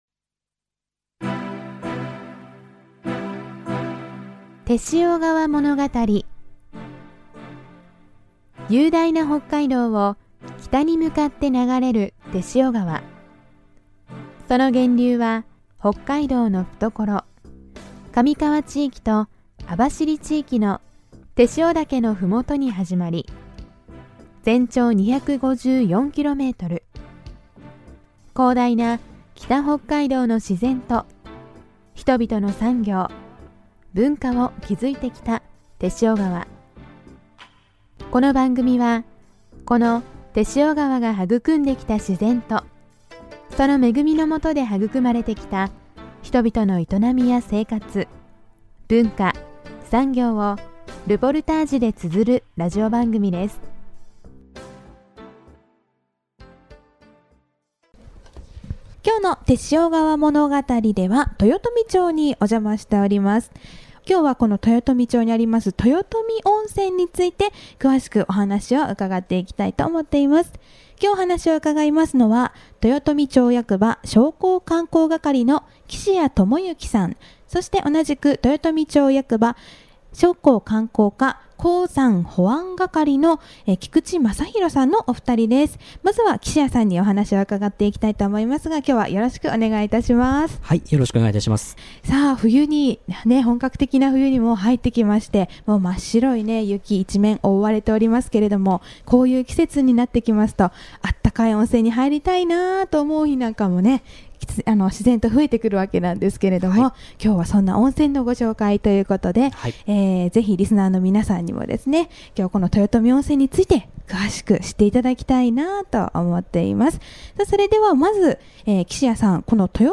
今回のものがたりでは、日本最北の温泉街である豊富温泉にお邪魔しました。豊富温泉郷は豊富町市街から南へ約６kmのところにあります。